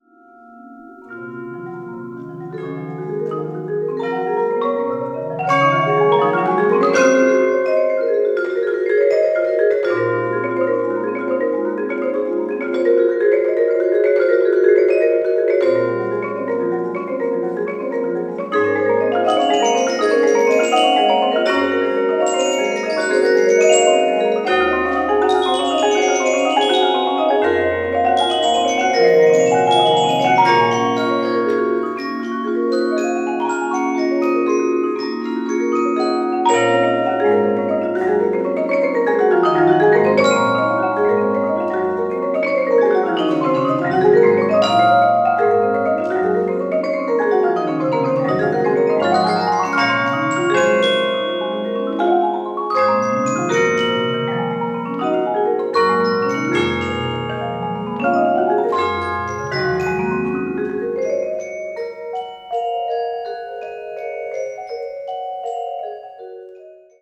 Tetrahedral Ambisonic Microphone
Recorded February 23, 2010, in the Bates Recital Hall at the Butler School of Music of the University of Texas at Austin.